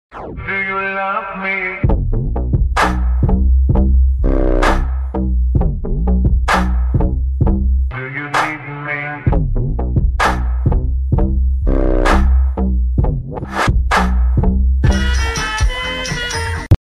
*Aggressive music* DO U NEED ME?!? *aggressive music again*